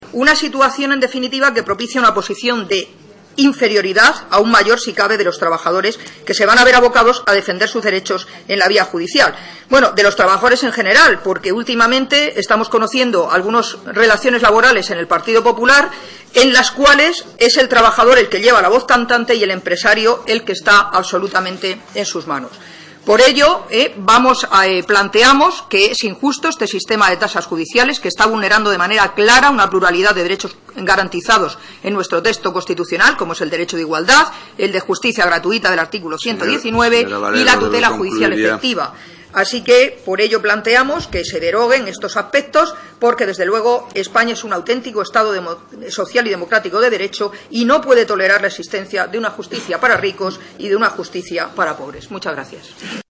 Magdalena Valerio en la Comisión de Justicia pide que el gobierno no aplique las tasas judiciales a los trabajadores en el orden social 26/02/2013